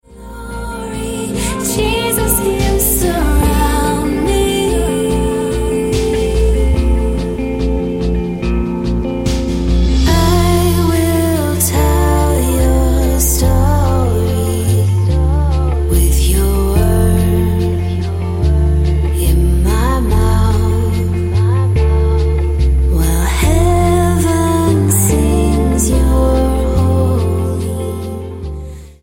Style: Pop Approach: Praise & Worship